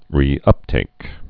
(rē-ŭptāk)